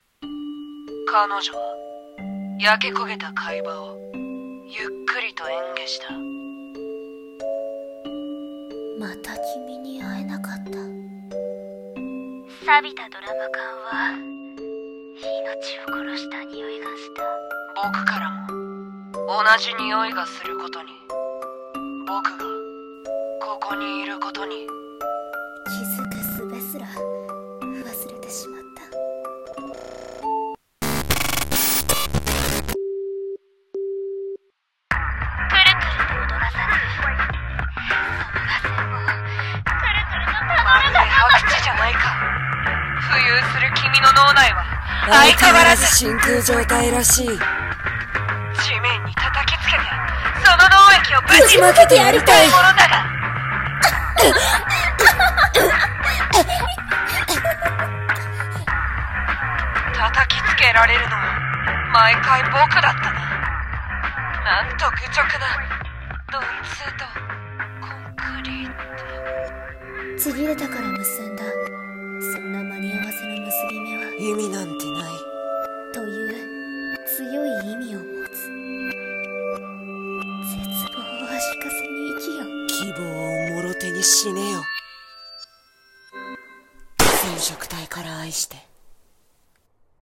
【声劇台本】テロメアのちぎり